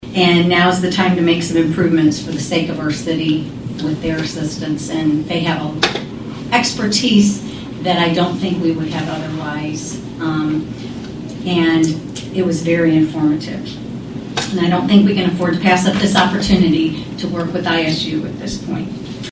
Mayor Garrett, speaking at Wednesday’s City Council meeting, says this was an amazing experience and a good opportunity for the city of Atlantic.